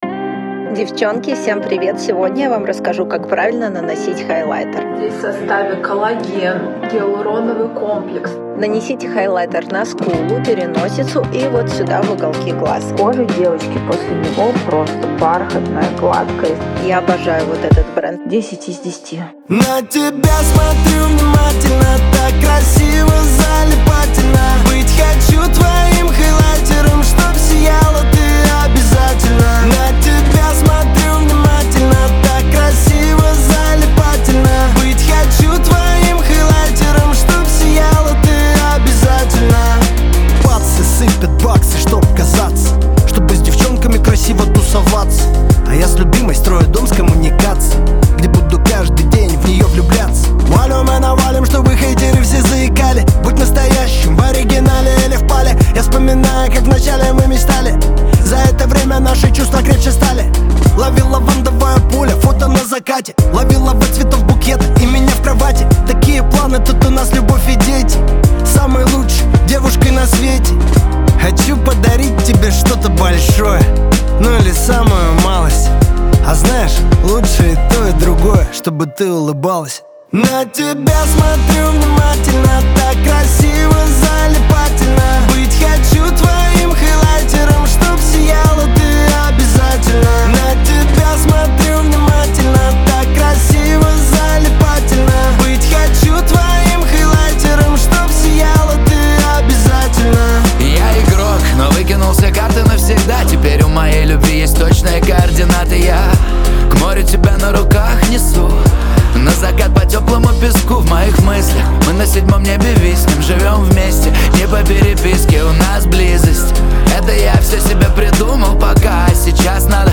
Жанр: ruspop